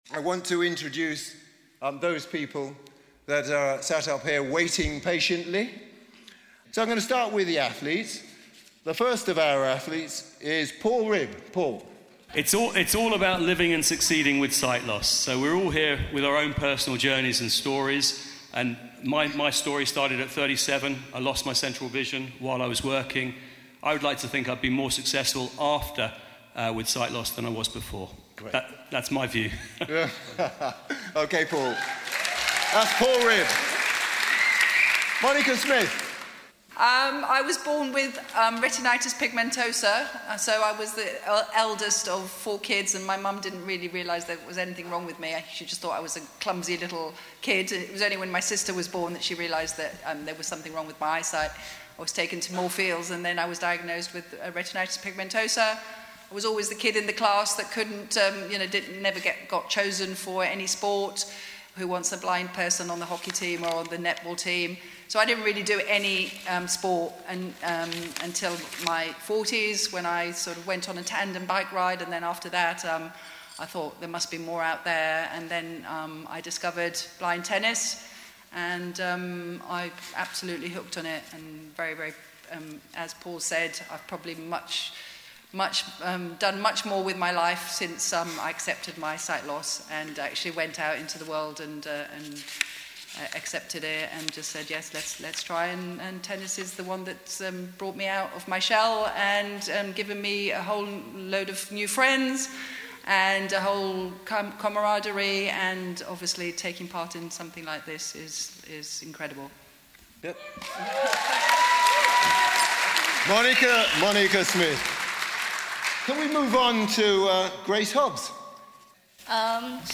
IBSA World Games 2023 - Closing Ceremony - Panel Introduction